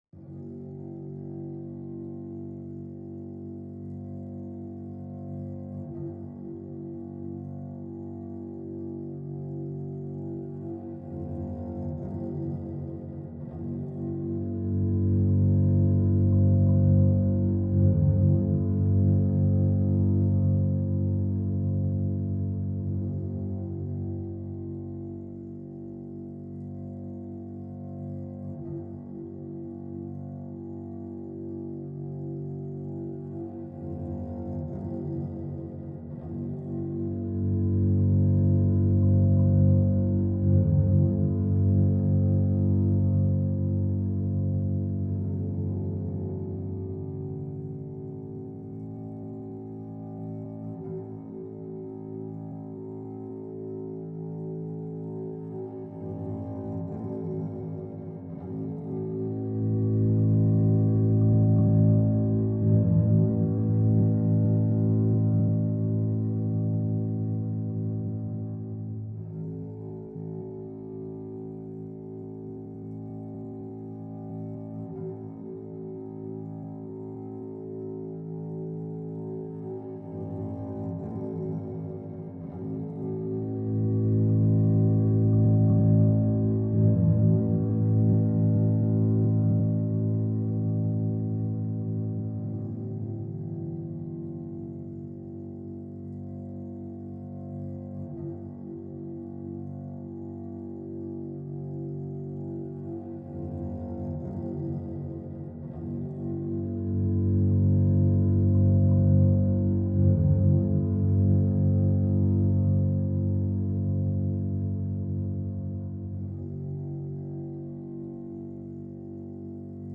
cello-hum.mp3